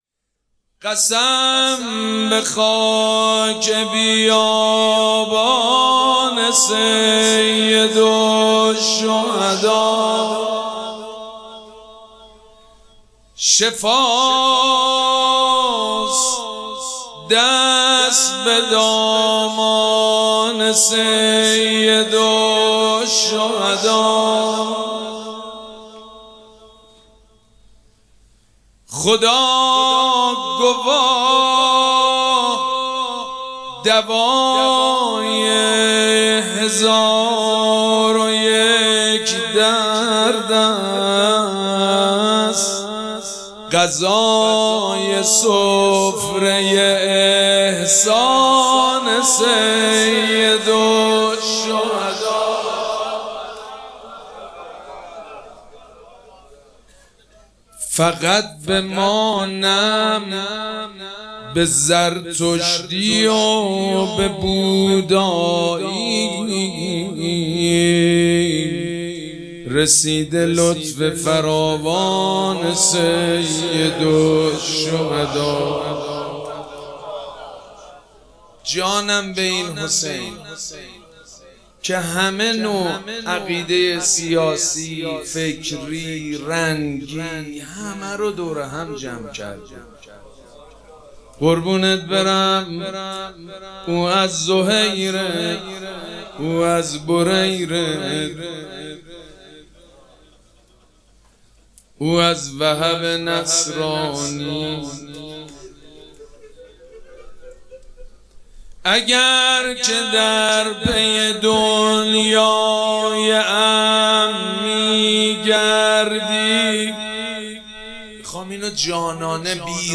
مراسم عزاداری شب دهم محرم الحرام ۱۴۴۷
شعر خوانی